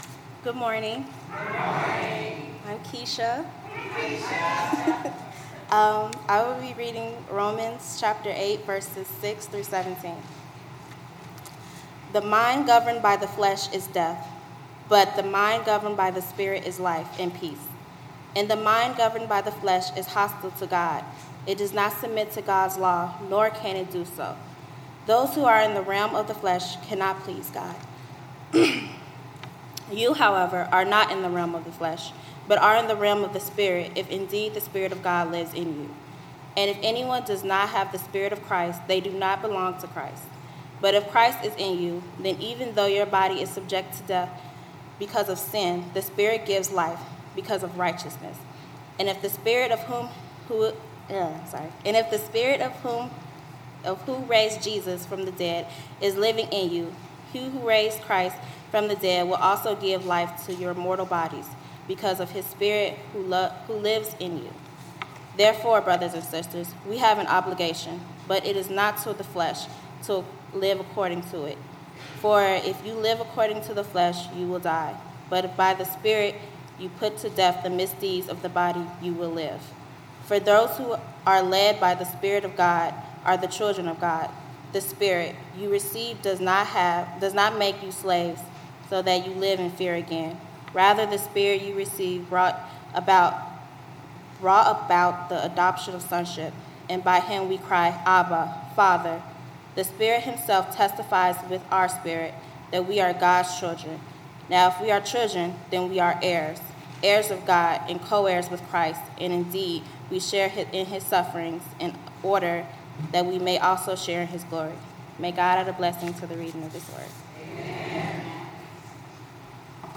Worship Service 6/18/17